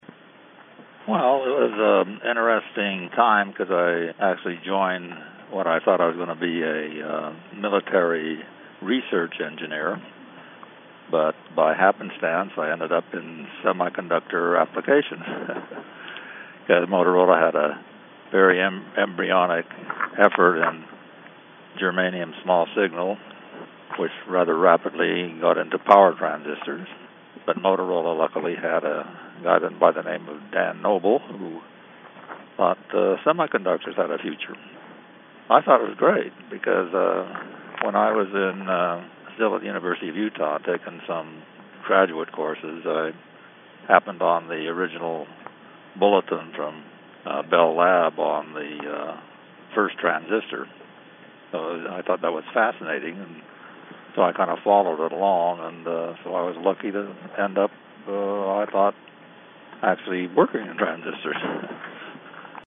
from a 2008 Interview with